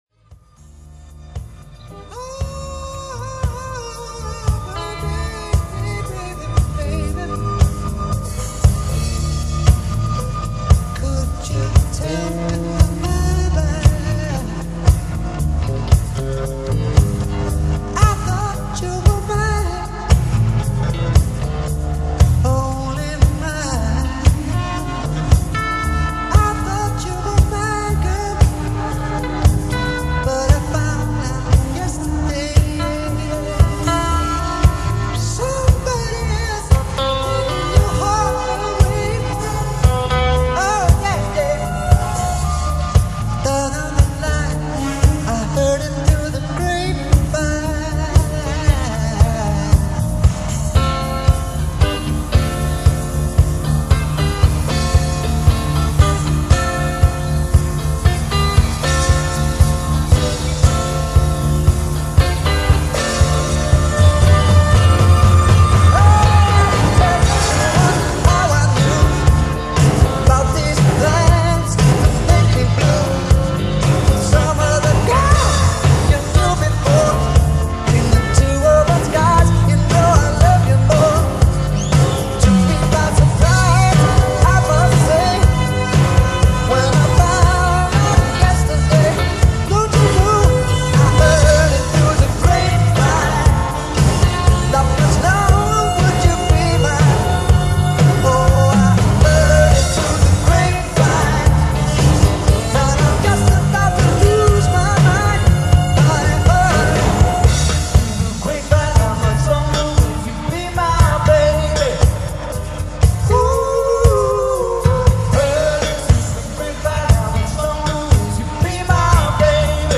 This one is the processed version.